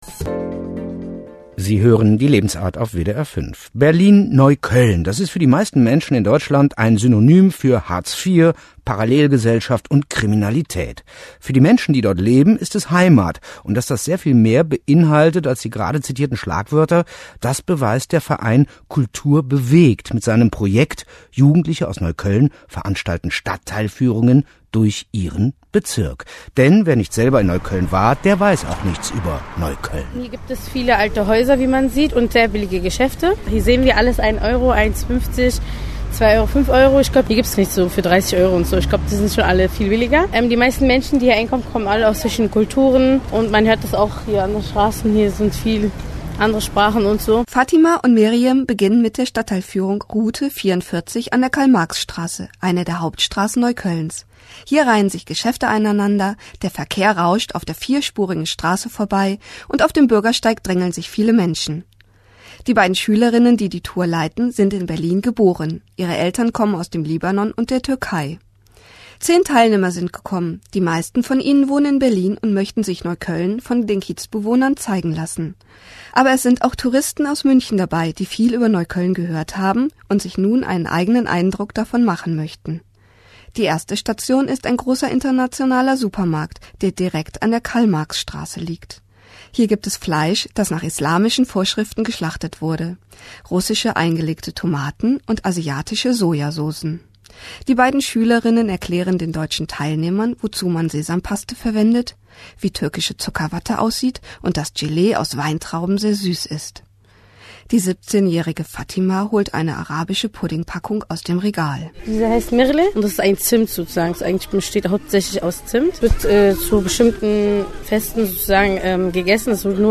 Reportage über die Tour "Alt und neu, laut und leise"